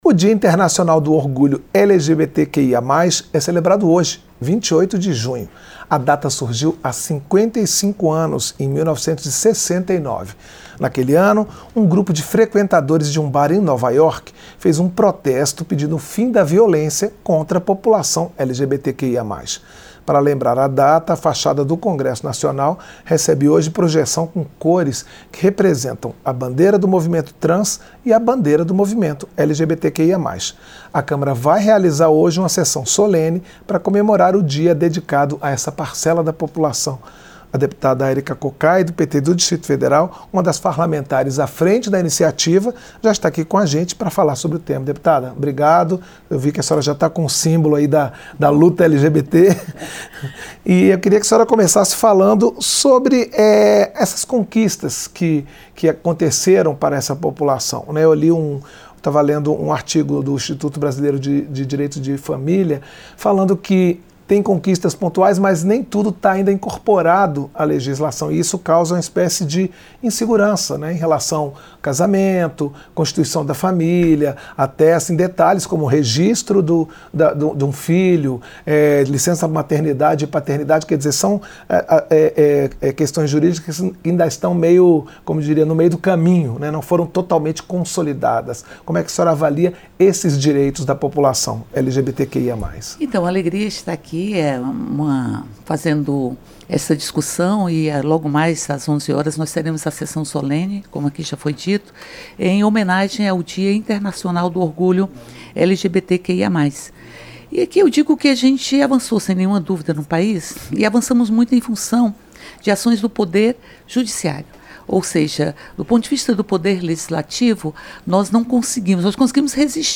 Entrevista - Dep. Erika Kokay (PT-DF)